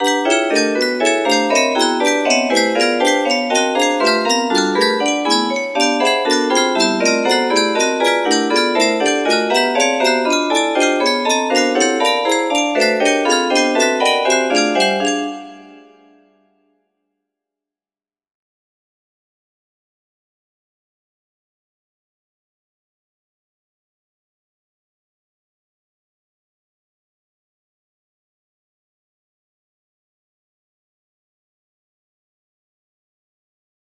P10 music box melody